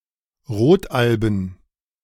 Rodalben (German: [ˈʁoːtˌʔalbm̩]